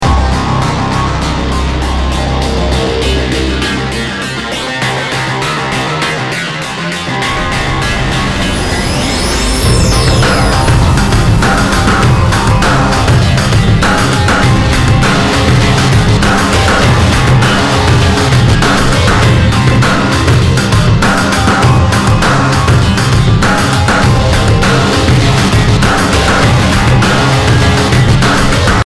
BPM 100